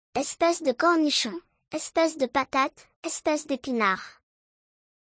• Espèce de cornichon = /Eh-spess-duh-Korn-knee-Shown/
• Espèce de patate = /Eh-spess-duh-pah-tat/
• Espèce d’épinard = /Eh-spess-day-pee-nar/